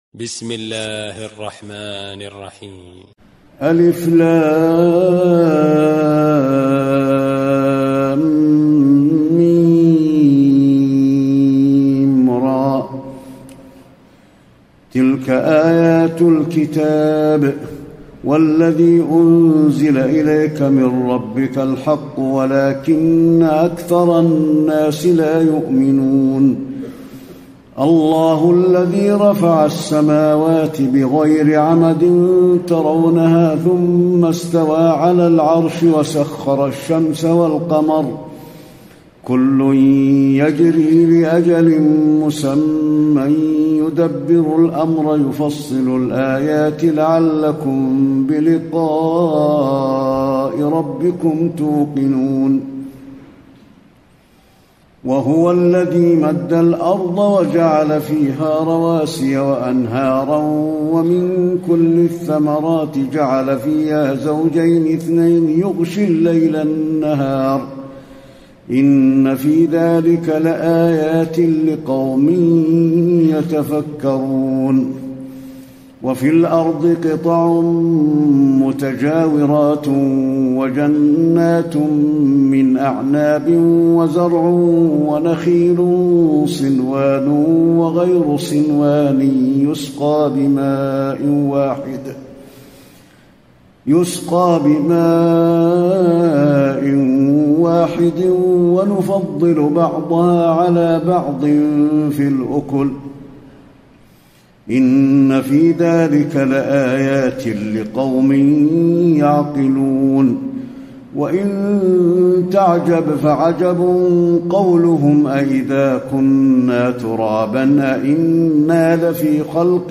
تراويح الليلة الثالثة عشر رمضان 1434هـ سورتي الرعد و إبراهيم كاملة Taraweeh 13 st night Ramadan 1434H from Surah Ar-Ra'd and Ibrahim > تراويح الحرم النبوي عام 1434 🕌 > التراويح - تلاوات الحرمين